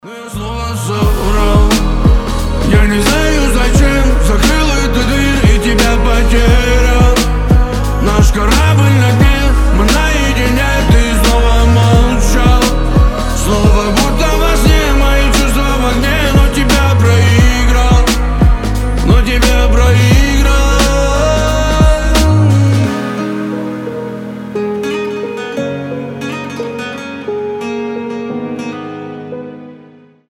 • Качество: 320, Stereo
грустные
мелодичные